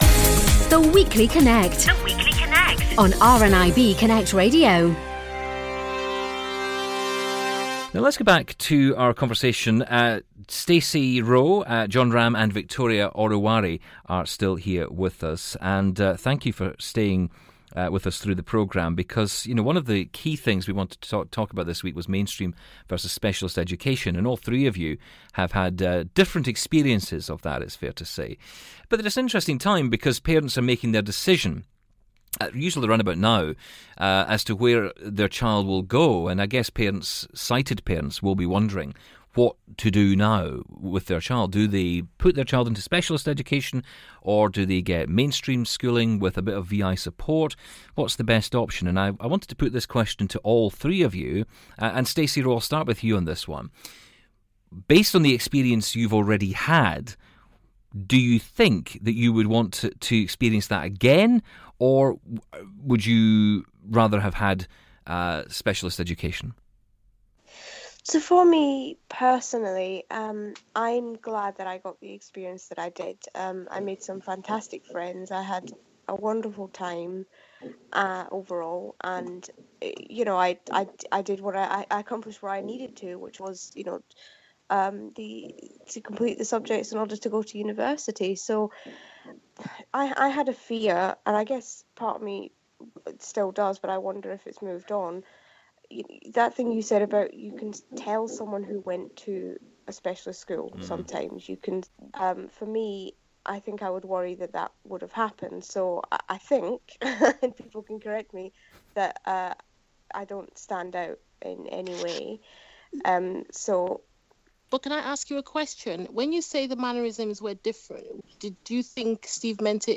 Discussion: Mainstream vs Specialist Schools - Part 2